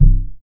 RAGGA SHORT.wav